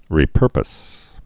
(rē-pûrpəs)